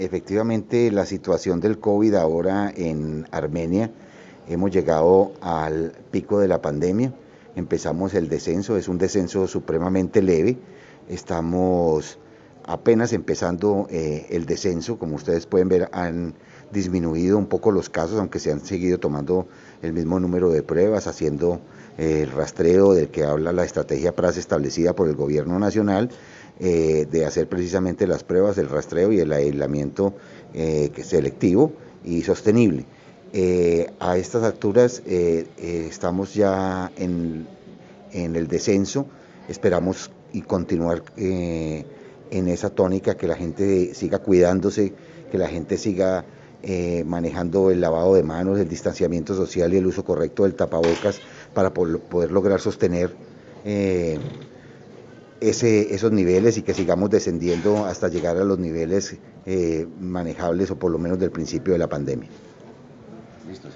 Audio: Gabriel Urrego Arroyave, Sec. Salud Municipal